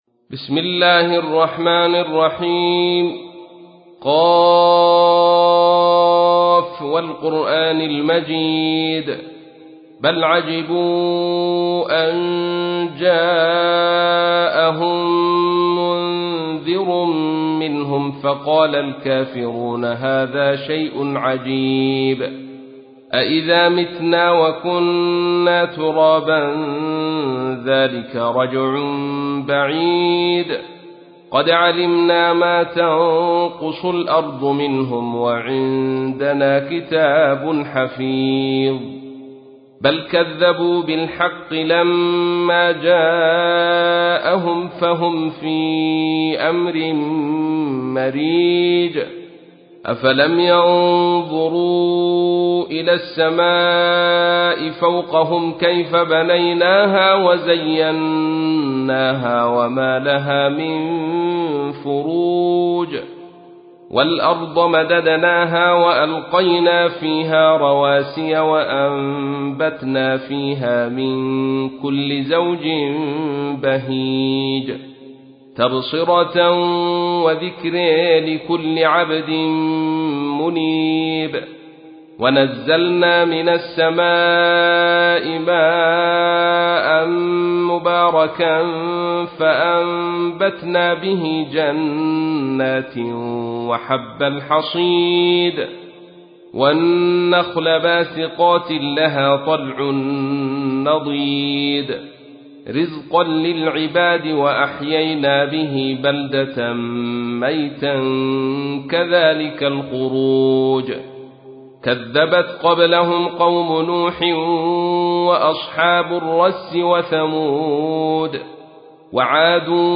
تحميل : 50. سورة ق / القارئ عبد الرشيد صوفي / القرآن الكريم / موقع يا حسين